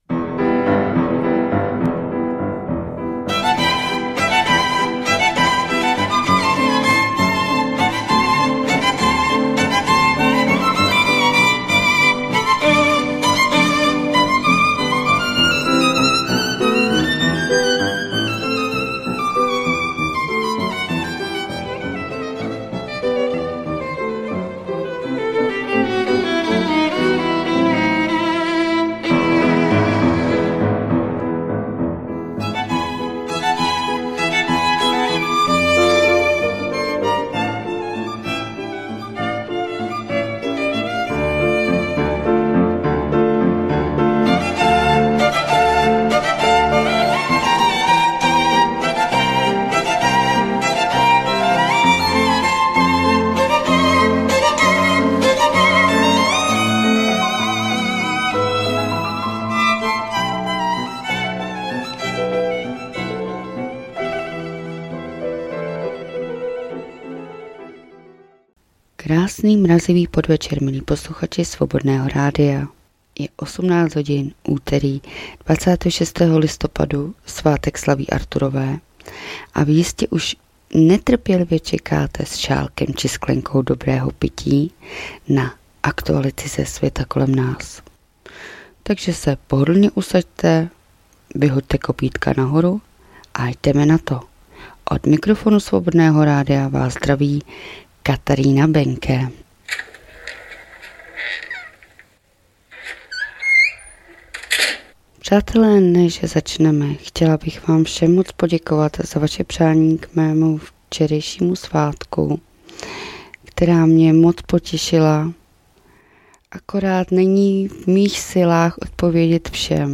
2024-11-26 - Zpravodajský přehled.